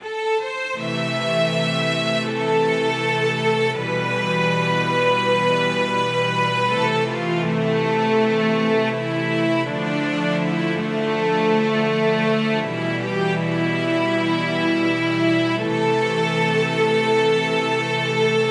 物悲しいストリングスな1曲です！
ループ：◎